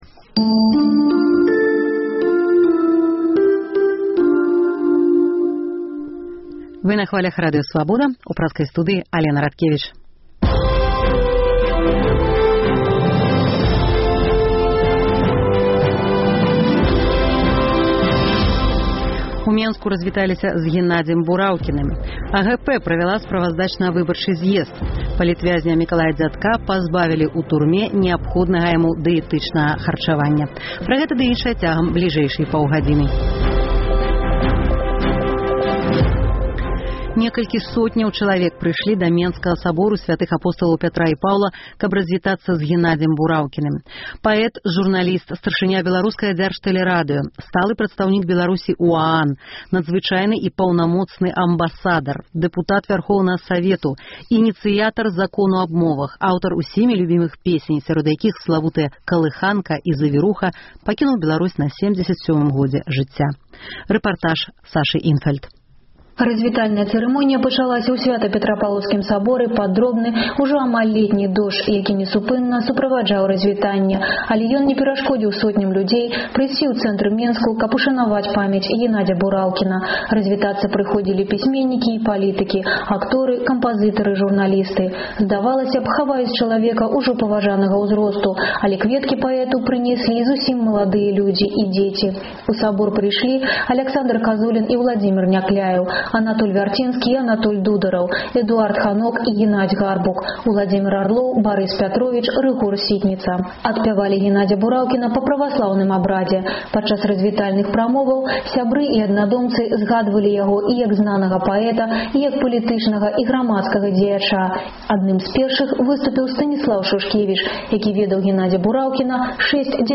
У праграме – рэпартаж з паніхіды, а таксама: справаздача са зьезду АГП, расповед пра сьвіныя хітрыкі на Гомельшчыне, меркаваньні берасьцейцаў наконт патрэбы забараніць паленьне, навіны з жыцьця палітвязьняў.